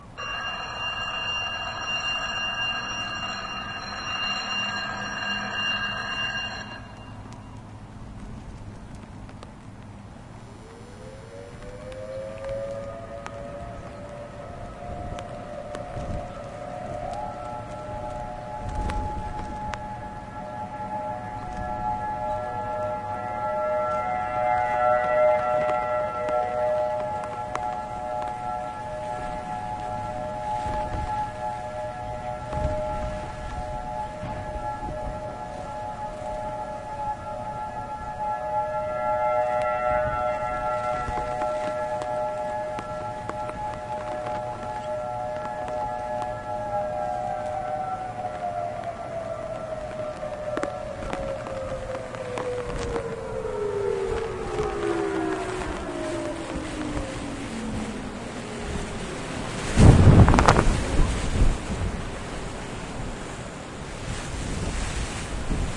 描述：警笛声
标签： 警报器